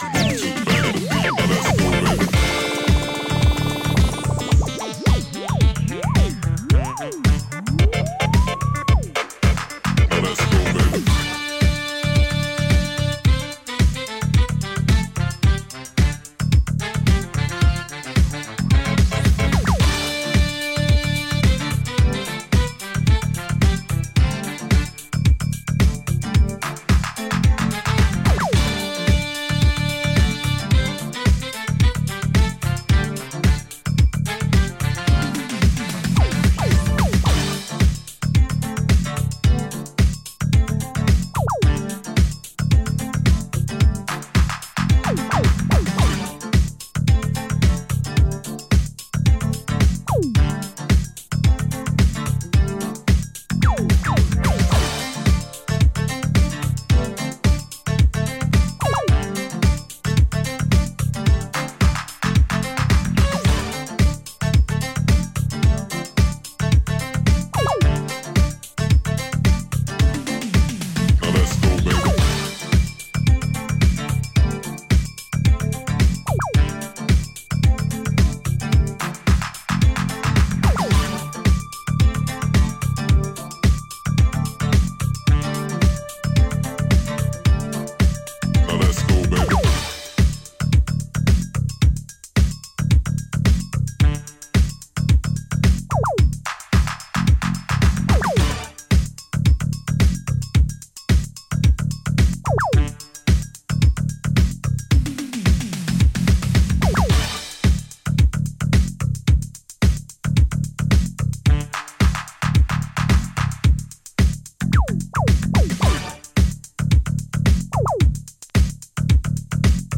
80'Sエレクトリック・ディスコ・ブギーでPOPなグルーヴ
ジャンル(スタイル) HOUSE / NU DISCO